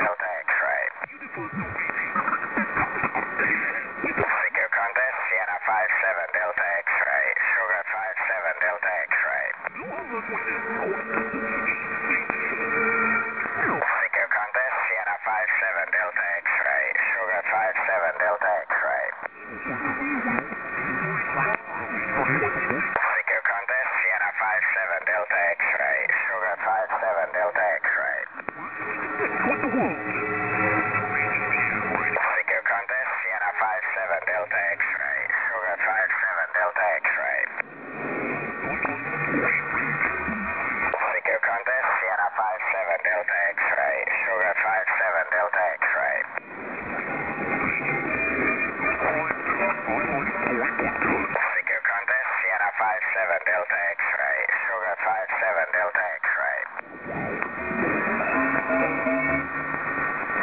WED-SDR in England
WAE DX Contest SSB